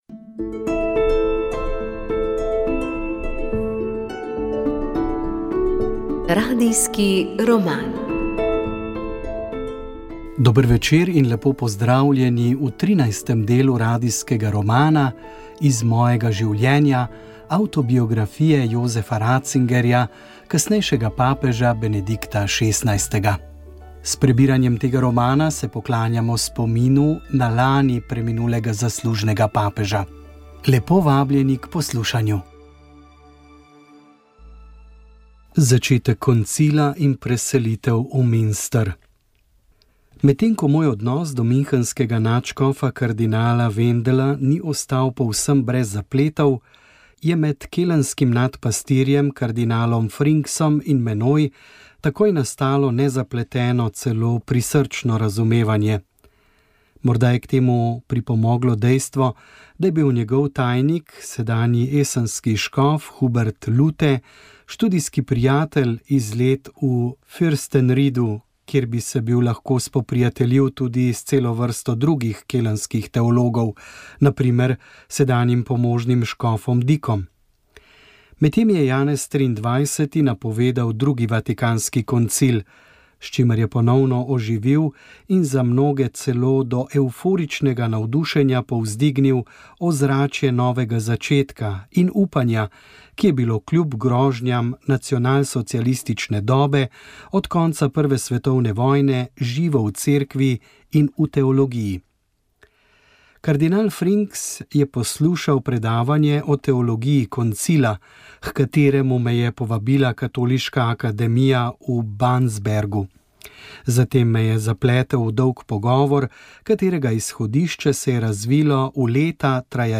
Za komentar izvolitve Slovenije v Varnostni svet smo prosili tudi nekdanjega predstavnika naše države v svetovni organizaciji Ernesta Petriča.